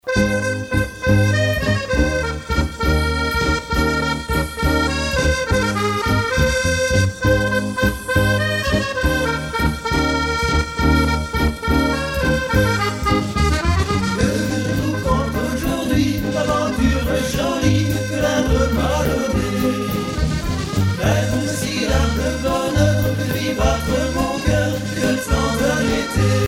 valse musette